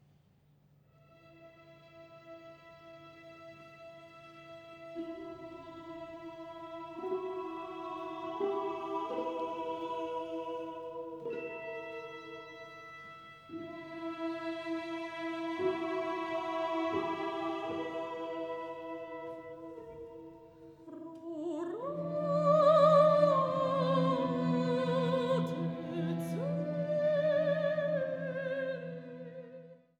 Zwei großbesetzte Werke für Soli, Chor und Orchester